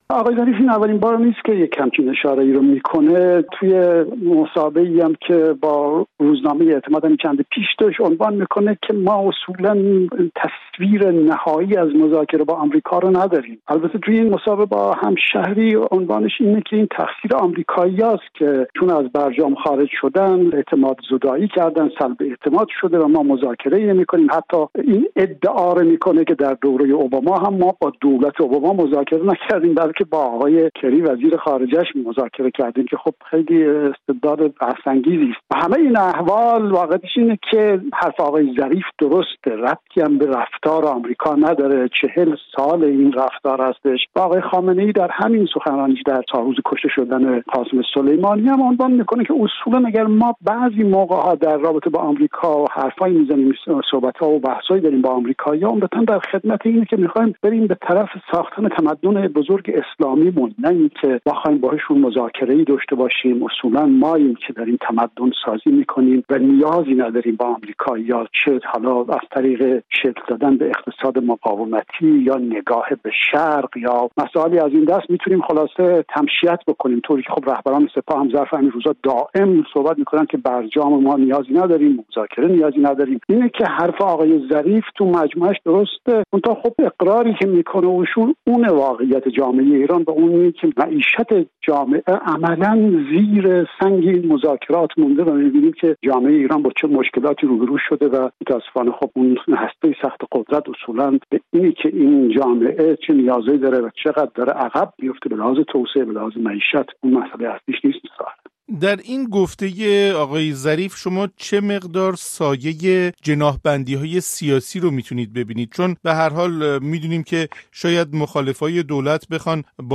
تحلیلگر سیاسی